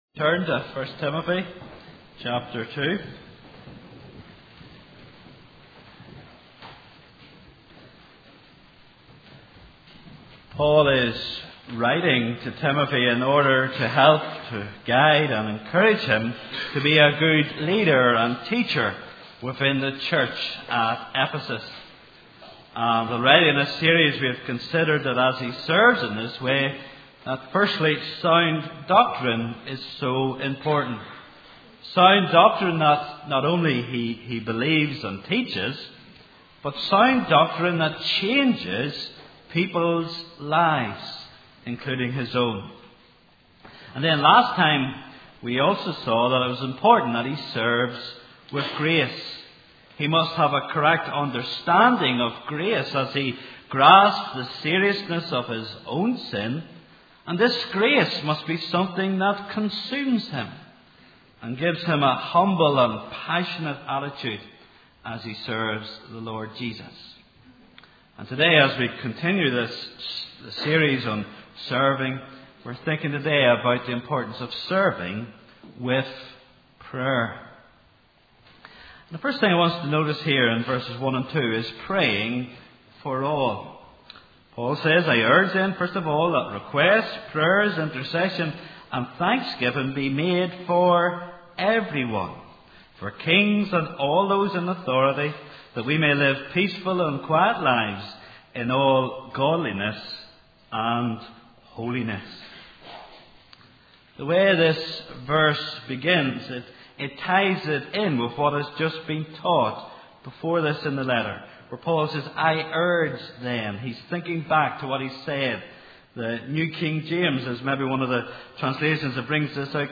Please note: this sermon was preached before Brookside adopted the English Standard Version as our primary Bible translation, the wording above may differ from what is spoken on the recording.